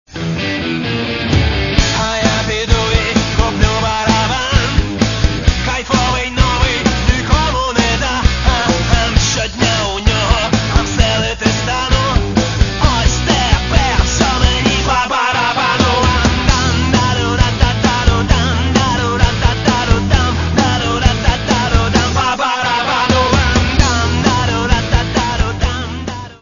Catalogue -> Rock & Alternative -> Rockabilly